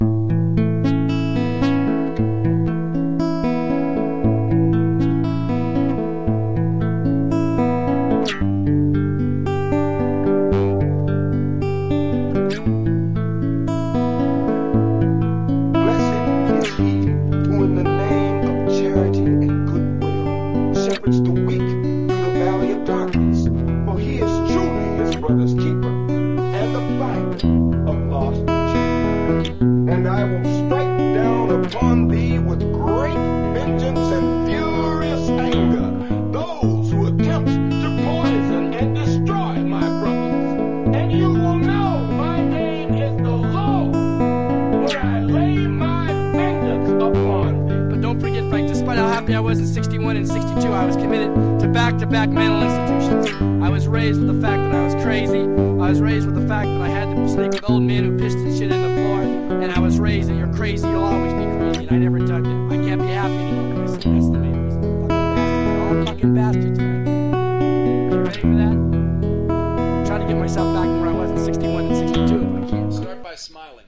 Tags: silly nutty weird noise sound collage